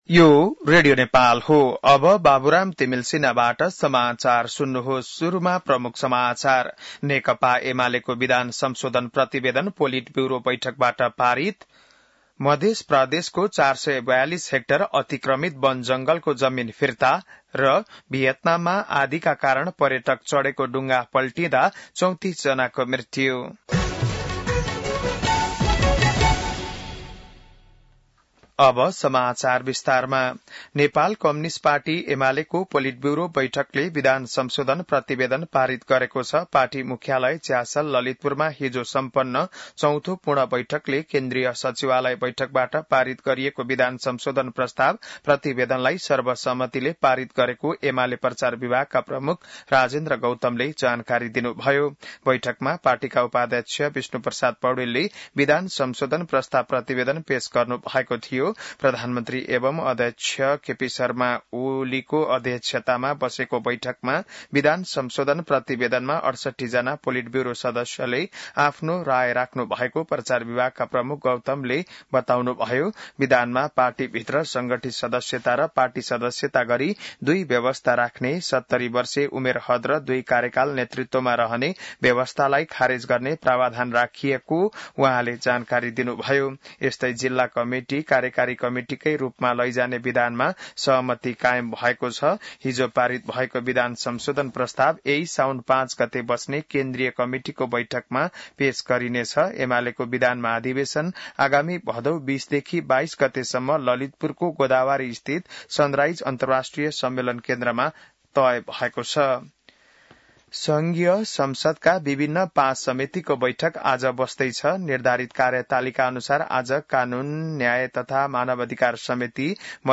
An online outlet of Nepal's national radio broadcaster
बिहान ९ बजेको नेपाली समाचार : ४ साउन , २०८२